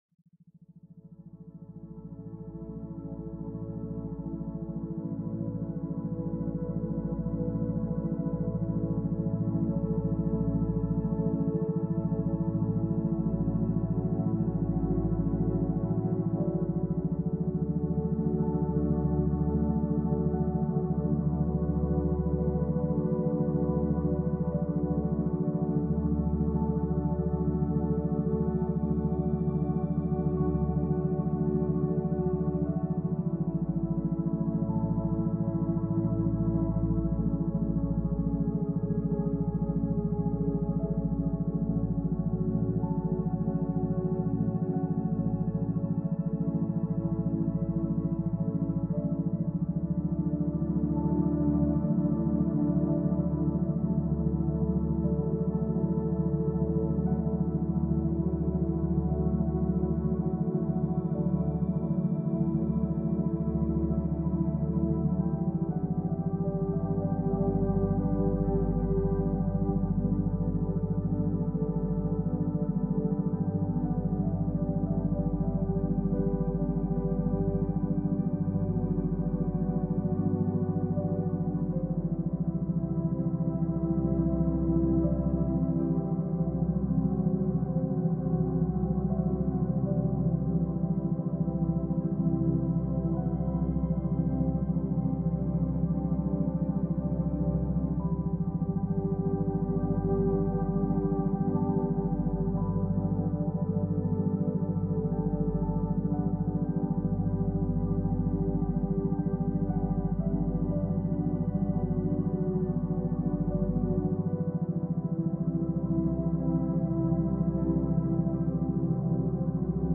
Hiver paisible longue durée · méthode neige et feu pour concentration profonde